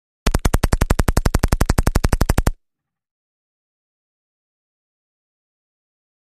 Mini-14 Automatic ( Silenced ): Single Burst; One Short, Silenced Burst Of Automatic Fire. Subsonic Rounds Could Sound Like Distant Burst. Close Up Perspective. Gunshots.